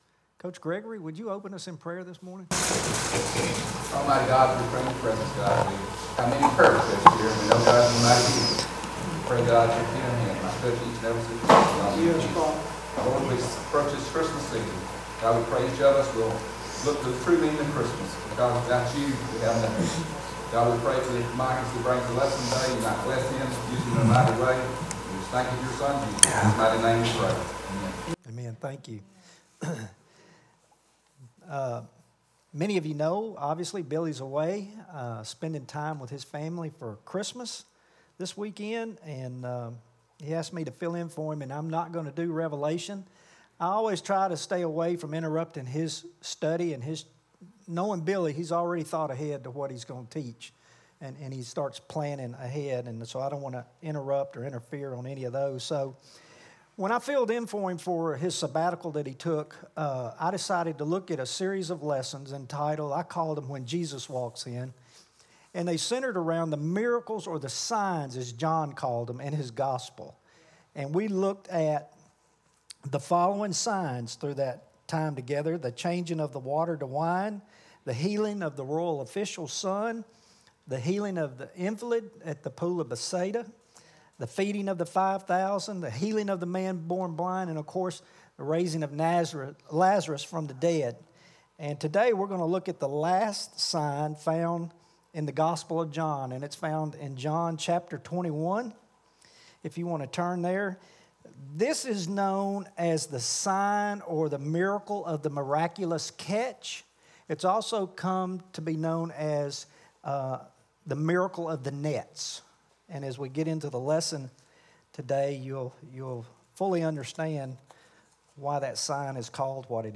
Bible Study, Sermon on the mount Mat. 6 Part 3